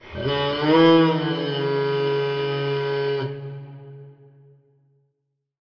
Minecraft Version Minecraft Version 25w18a Latest Release | Latest Snapshot 25w18a / assets / minecraft / sounds / item / goat_horn / call7.ogg Compare With Compare With Latest Release | Latest Snapshot